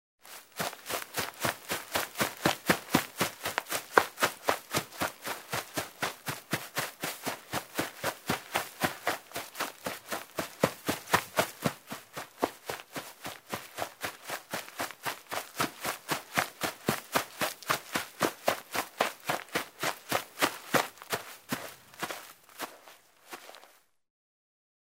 Звуки травы
Шорох бега по траве